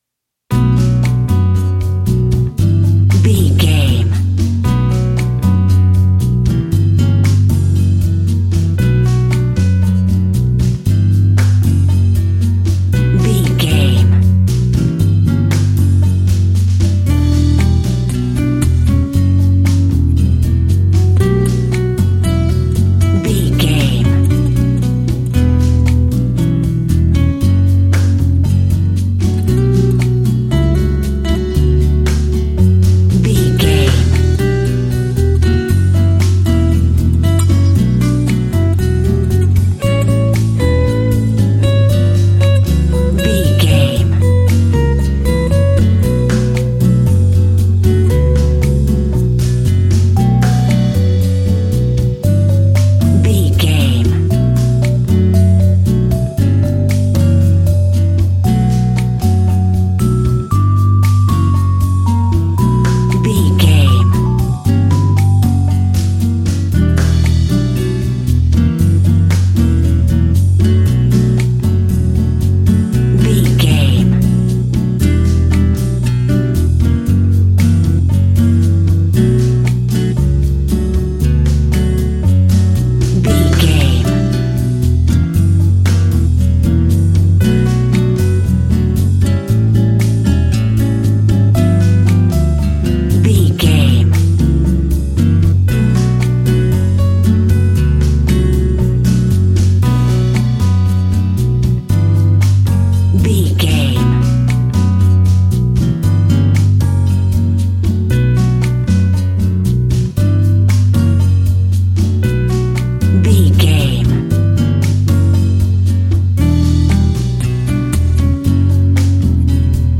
An exotic and colorful piece of Espanic and Latin music.
Aeolian/Minor
flamenco
romantic
maracas
percussion spanish guitar
latin guitar